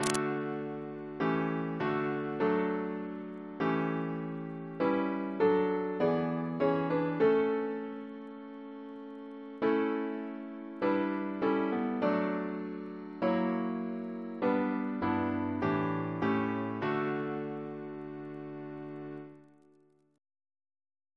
Double chant in D Composer: Sir Joseph Barnby (1838-1896), Precentor of Eton, Principal of the Guildhall School of Music Reference psalters: ACB: 379